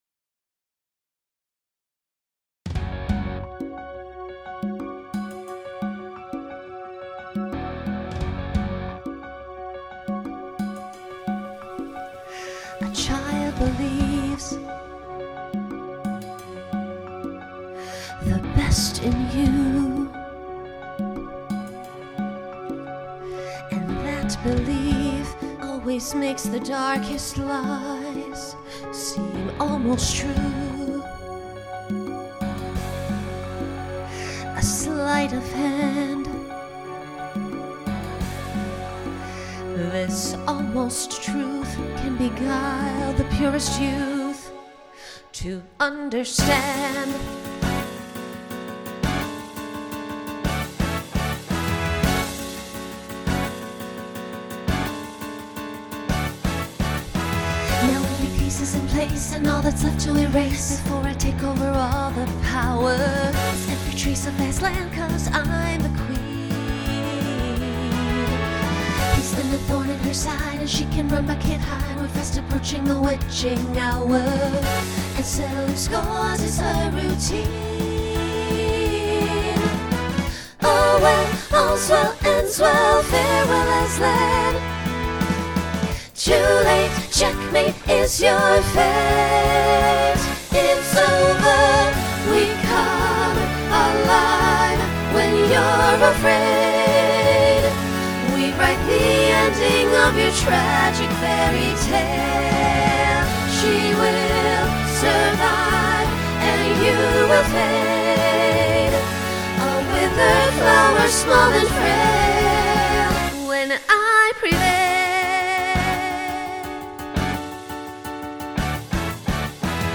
Genre Broadway/Film Instrumental combo
Transition Voicing SSA